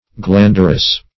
Search Result for " glanderous" : The Collaborative International Dictionary of English v.0.48: Glanderous \Glan"der*ous\, a. Of or pertaining to glanders; of the nature of glanders.